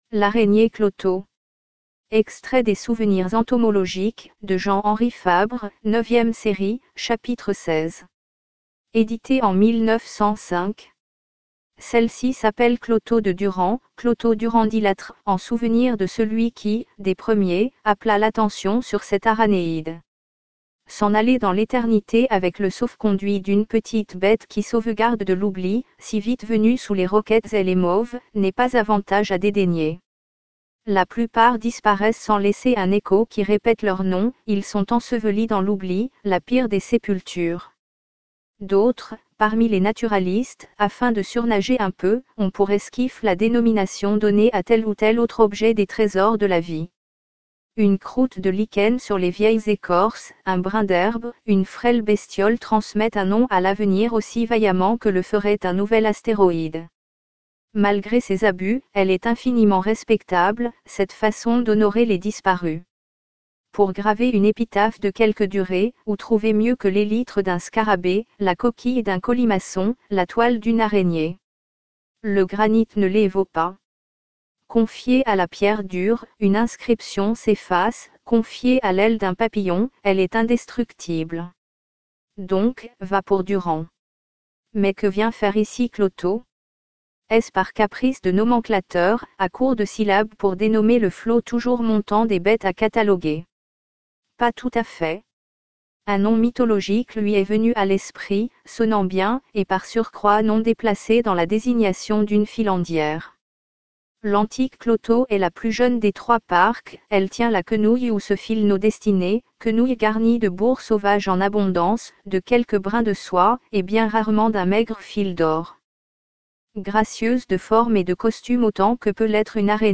Souvenirs entomologiques de Jean-Henri FABRE : L'Araignée clotho, Textes audio